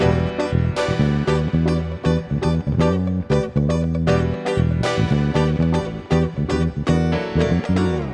12 Backing PT4.wav